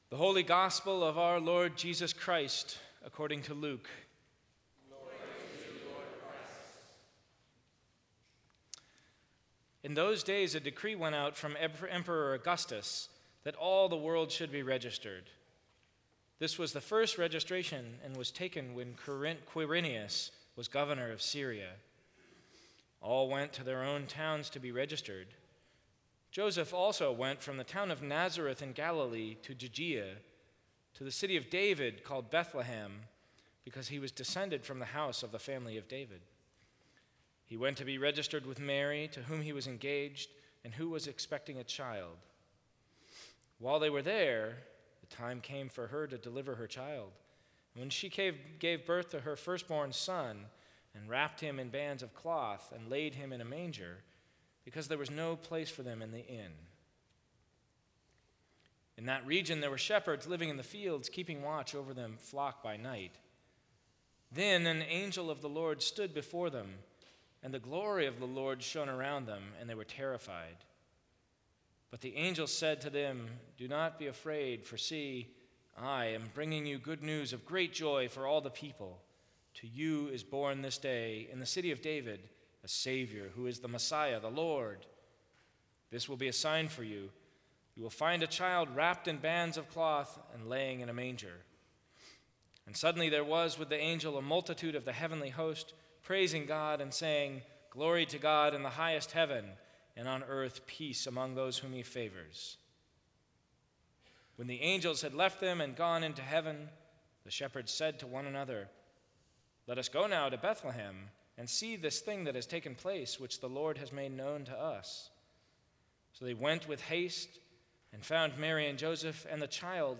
Sermons from St. Cross Episcopal Church December 24, 2014.